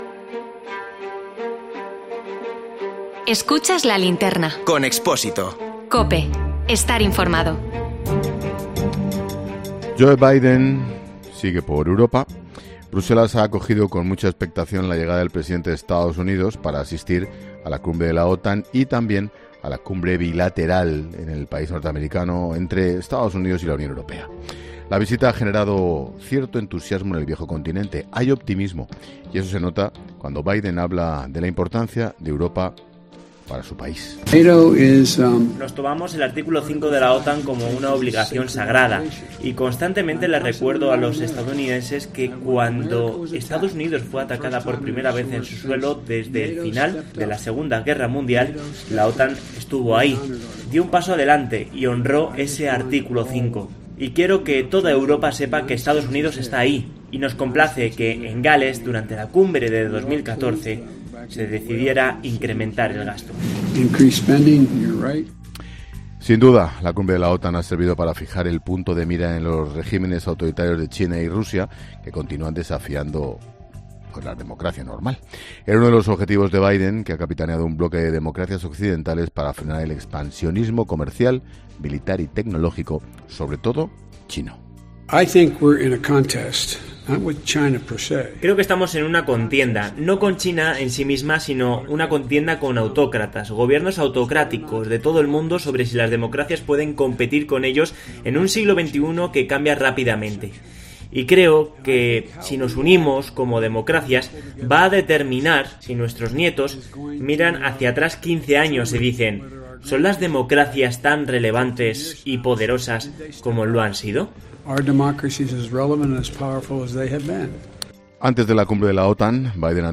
En nuestro tema del día, analizamos con expertos cómo es la política exterior de EEUU con Joe Biden en el nuevo gobierno y qué diferencias tiene con...